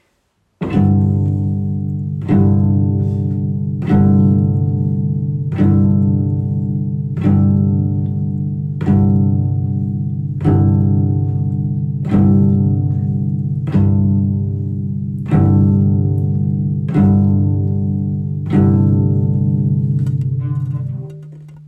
Beispiele Cello
Summ, summ, summ (pizzicato - gezupft)